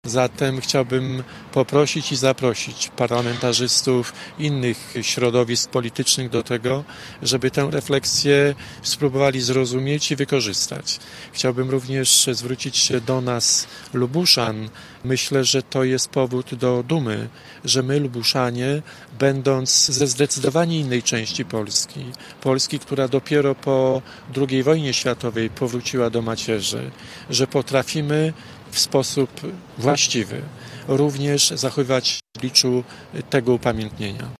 Poseł Jacek Kurzępa mówił dziś w Radiu Zachód, że ten pomnik powinien łączyć wszystkich Polaków.